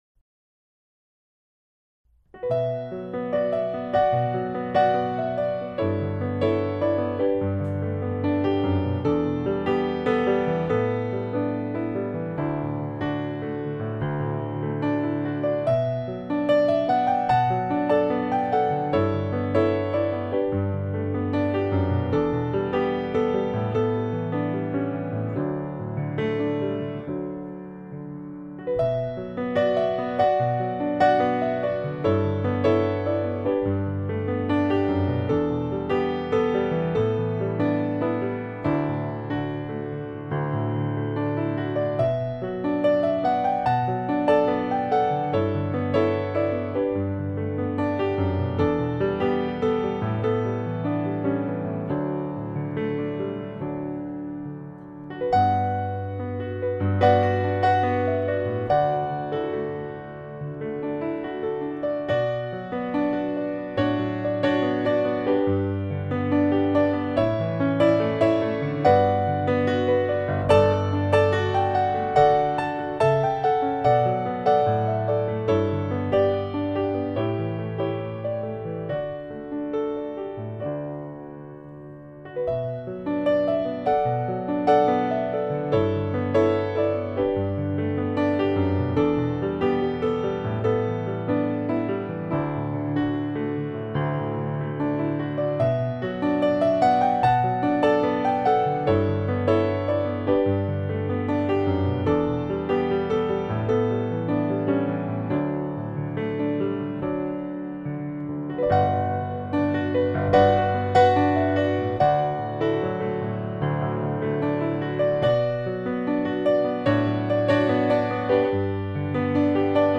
a collection of original piano music with a romantic touch
solo piano